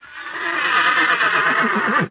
horse-whinny-3.ogg